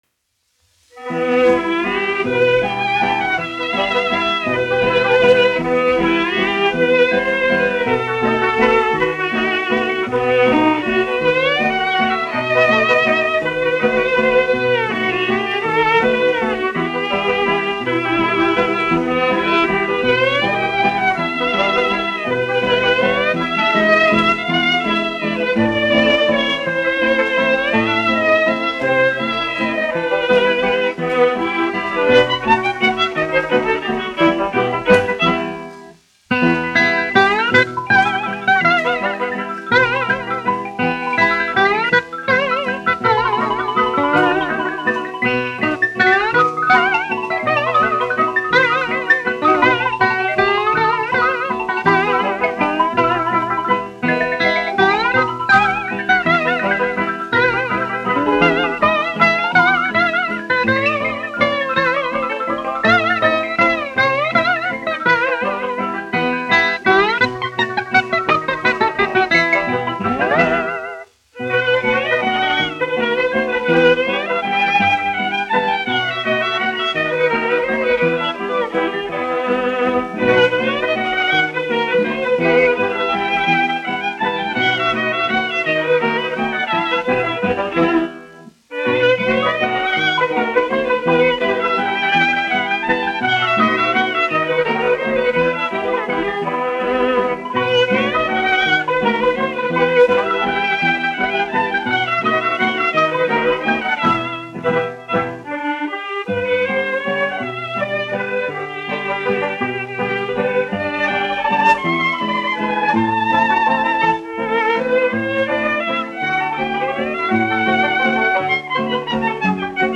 1 skpl. : analogs, 78 apgr/min, mono ; 25 cm
Populārā instrumentālā mūzika
Skaņuplate
Latvijas vēsturiskie šellaka skaņuplašu ieraksti (Kolekcija)